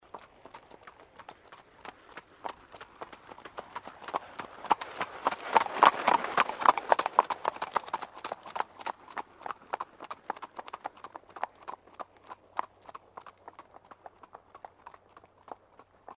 На этой странице собрана коллекция натуральных звуков лошадей.
Проход лошадей рысью